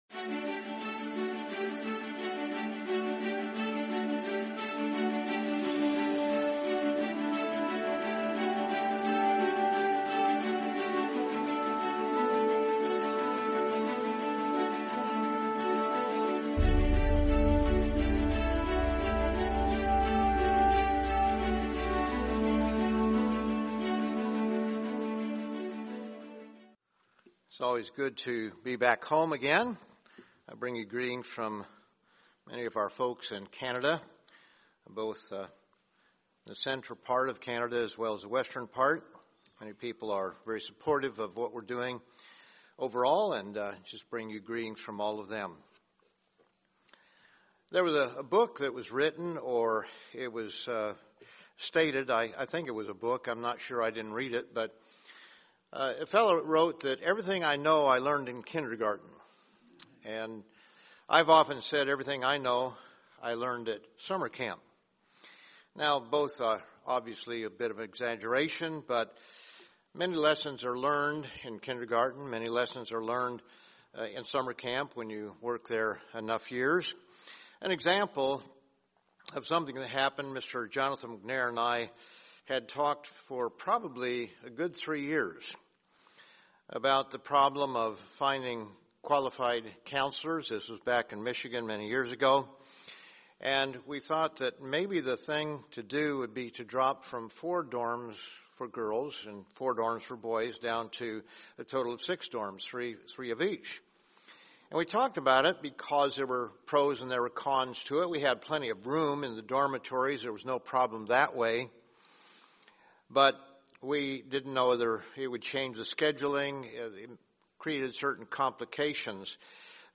Sermon Unintended Consequences